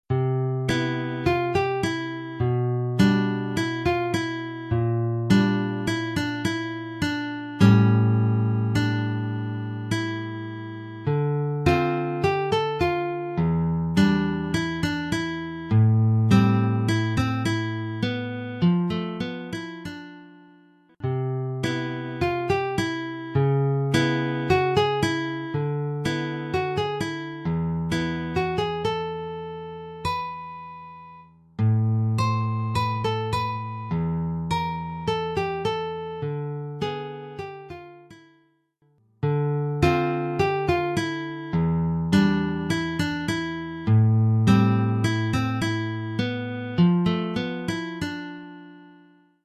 1 titre, guitare solo : partie de guitare
Oeuvre pour guitare solo.